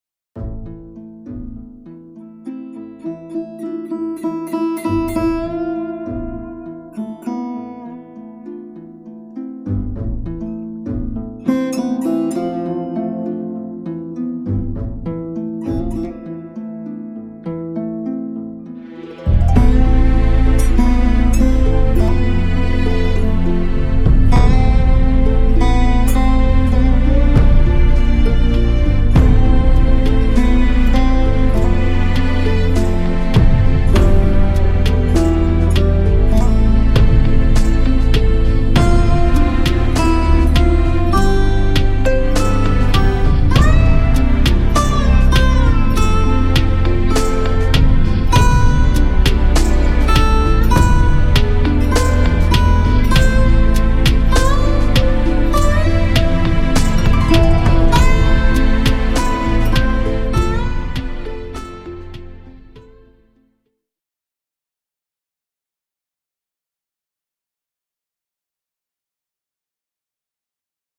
Artist: Instrumental,